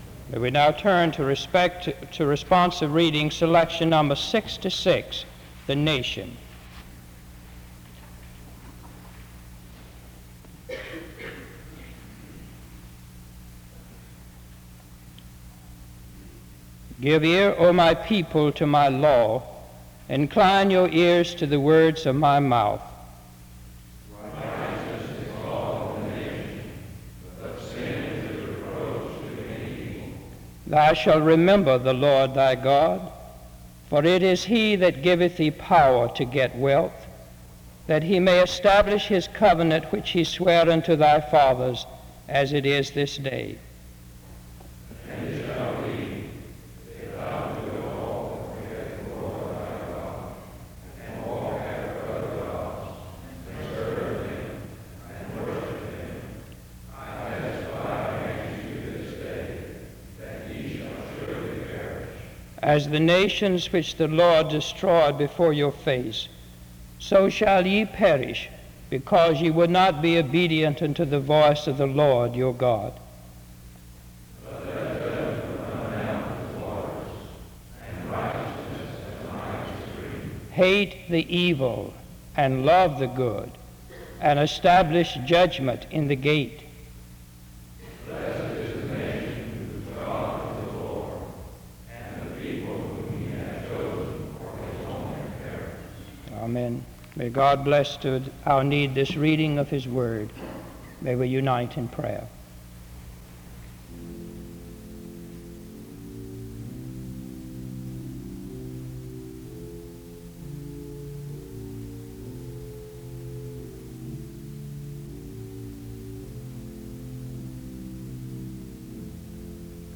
The service opens with a responsive reading from 0:00-1:40.
A prayer is offered from 1:41-4:54.
The service ends with music from 23:06-24:53.
In Collection: SEBTS Chapel and Special Event Recordings SEBTS Chapel and Special Event Recordings